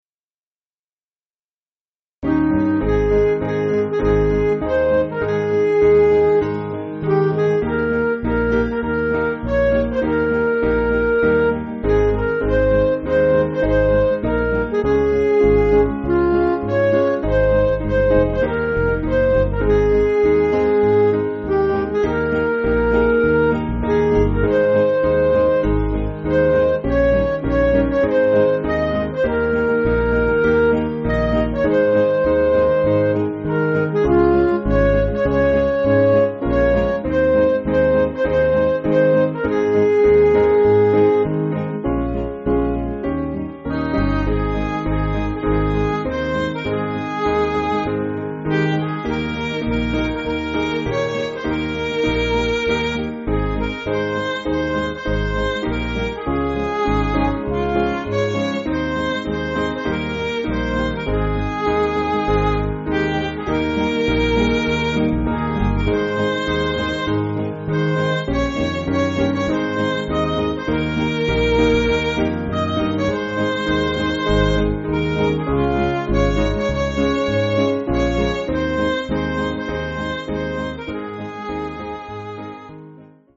Piano & Instrumental
(CM)   4/Ab
Midi